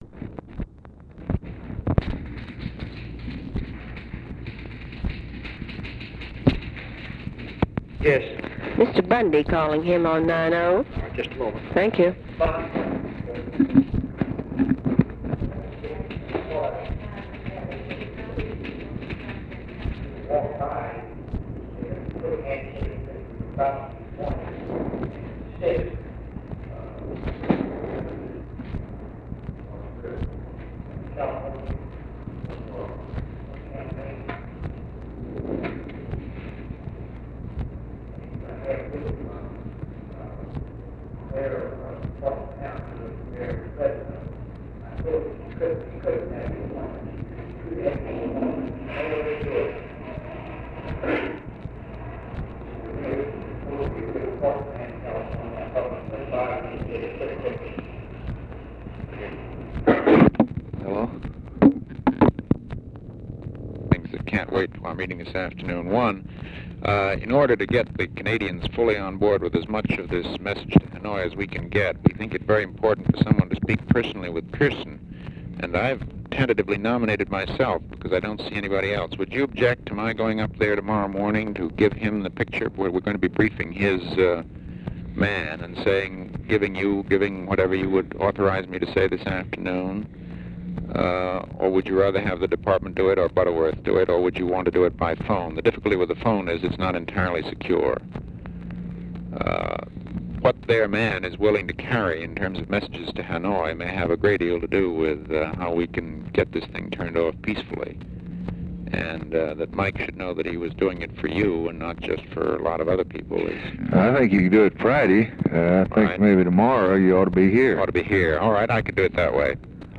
Conversation with MCGEORGE BUNDY and OFFICE CONVERSATION, May 27, 1964
Secret White House Tapes